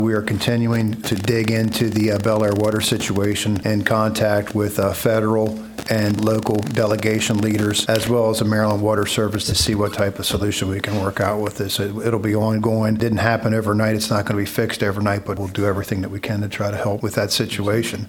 Commission President Dave Caporale ended Thursday night’s Allegany County Commissioner meeting by addressing the ongoing Bel Air Water Issues. Caporale said they are looking into what they can do to assist on the local level and reaching out to state and federal partners, but there’s no easy solution…